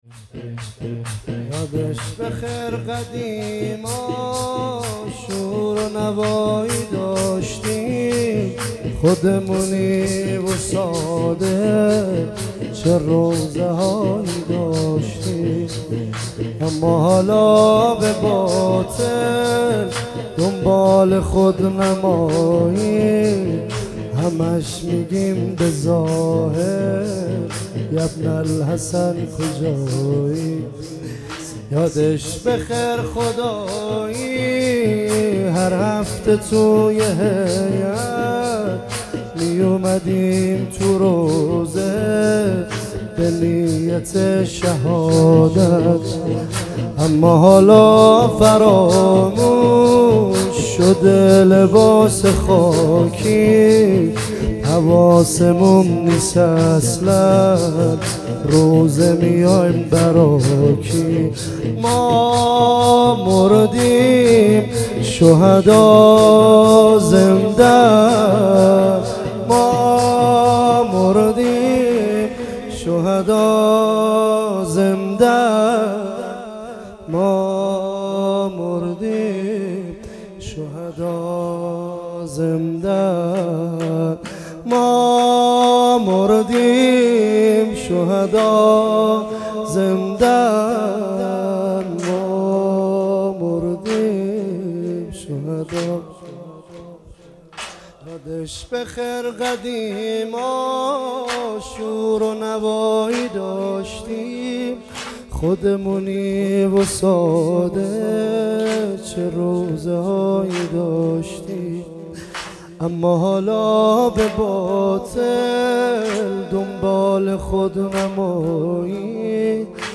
مراسم شب ۲۸ محرم ۱۳۹۷